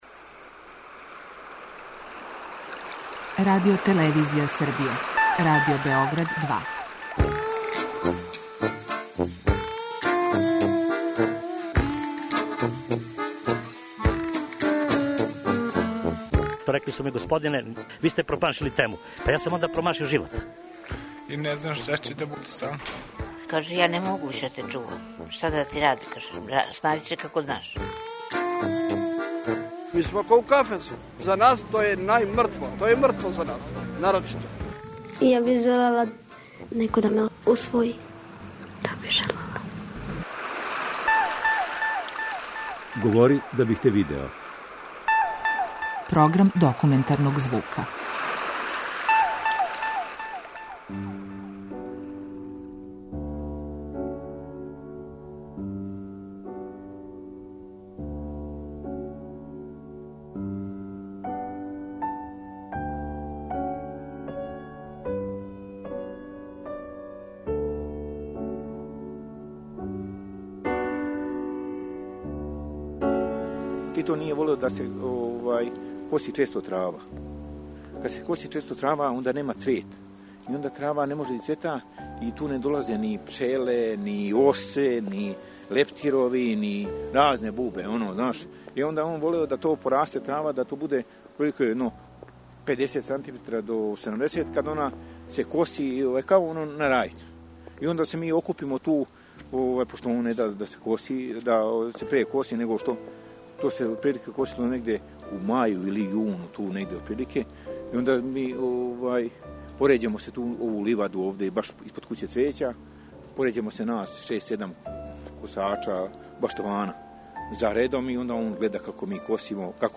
Документарни програм
Данас емитујемо други део репортаже.